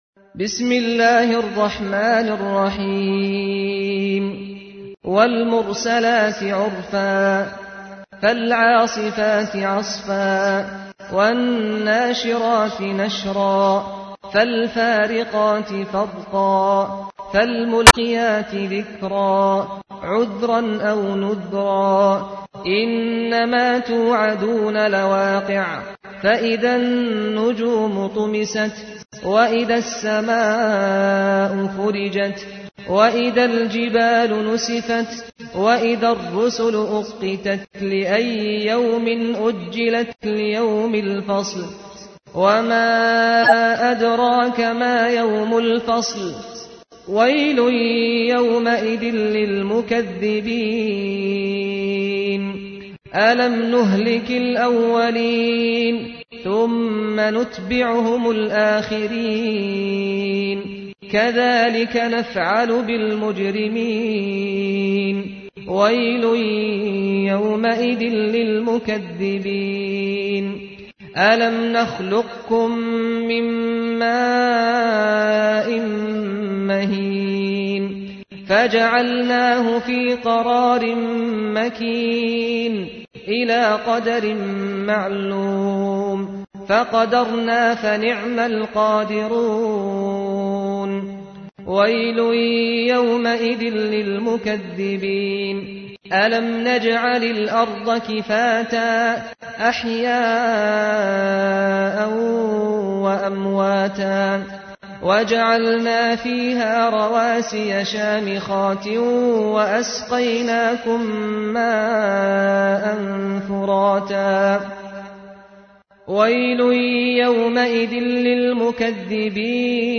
تحميل : 77. سورة المرسلات / القارئ سعد الغامدي / القرآن الكريم / موقع يا حسين